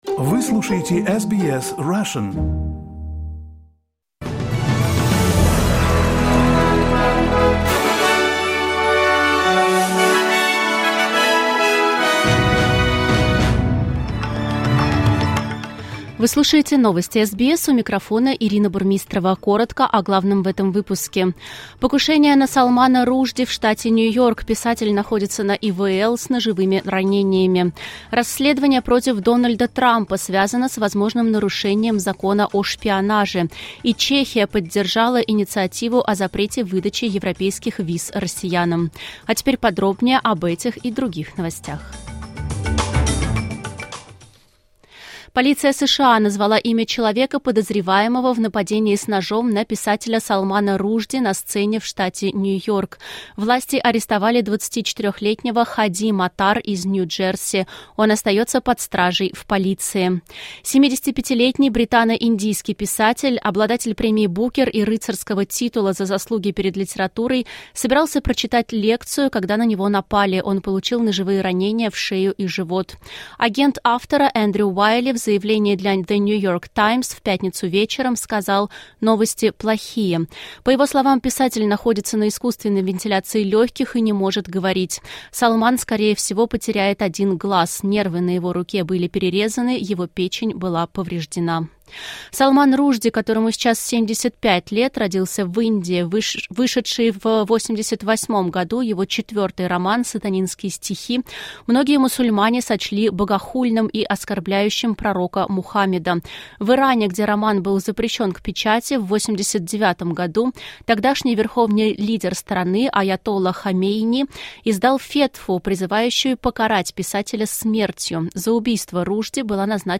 SBS News in Russian - 13.08.2022